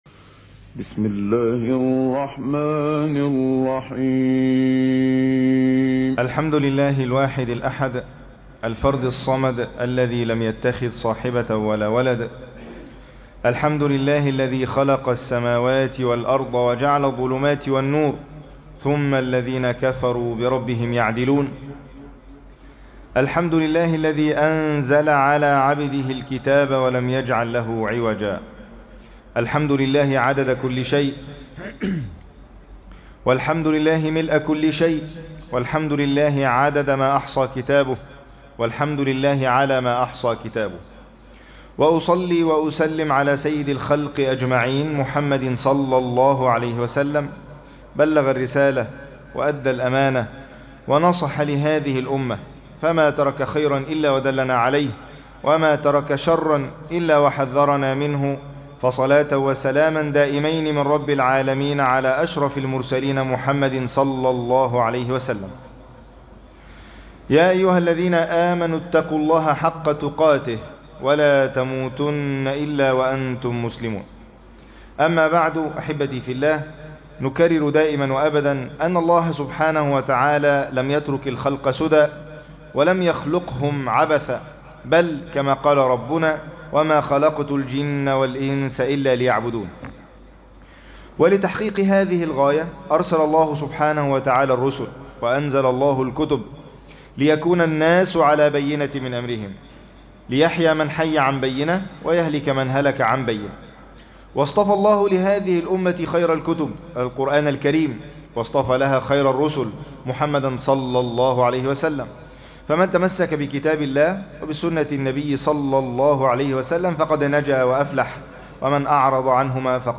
تفاصيل المادة عنوان المادة تفسير سورة العلق | خطبة جمعة تاريخ التحميل الجمعة 6 سبتمبر 2024 مـ حجم المادة 15.51 ميجا بايت عدد الزيارات 357 زيارة عدد مرات الحفظ 109 مرة إستماع المادة حفظ المادة اضف تعليقك أرسل لصديق